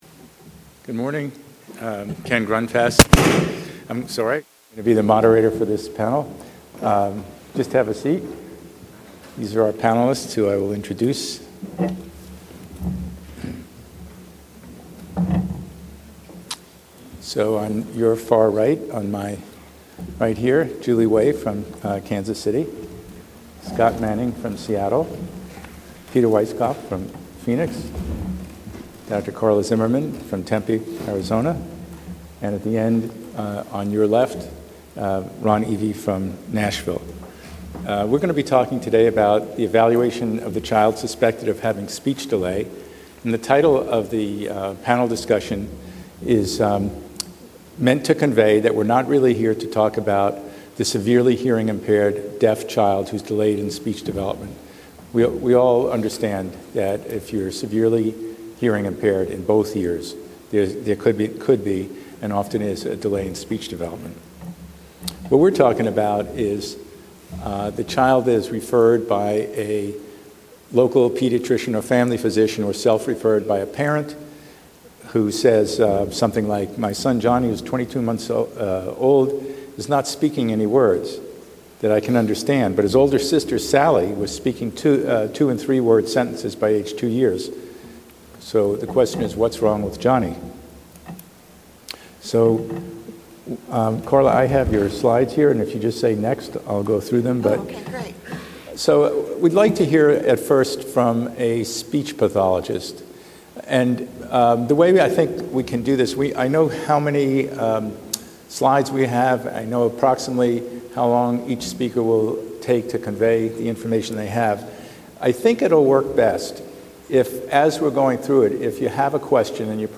A panel of experts at the Triological Society's 2013 Combined Sections Meeting discuss the importance of early intervention in children with speech delay.